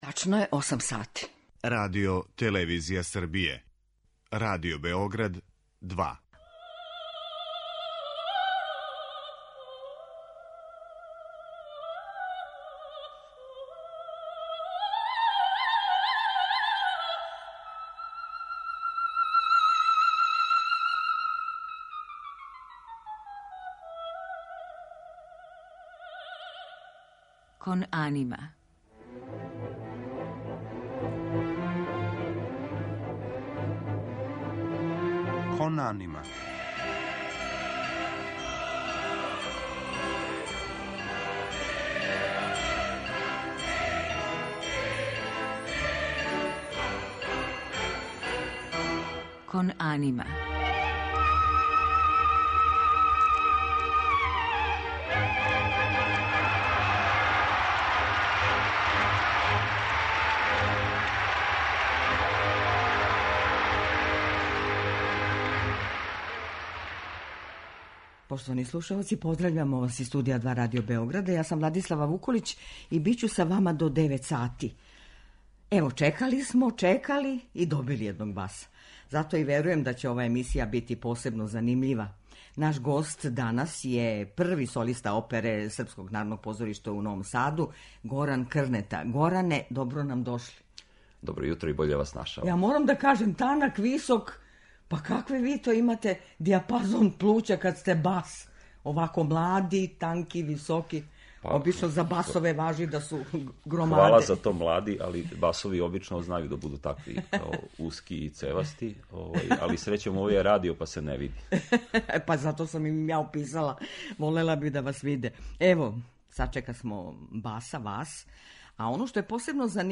Слушаћете и арије из опера Волфанга Амадеуса Моцарта, Ђузепа Вердија, Петра Иљича Чајковског